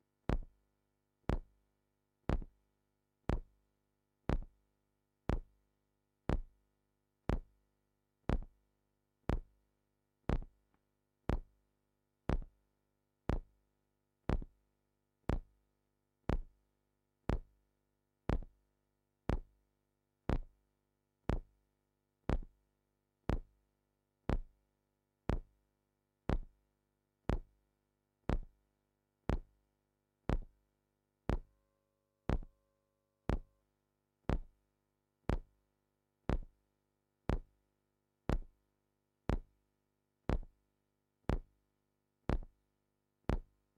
Звук размеренного тиканья в состоянии гипноза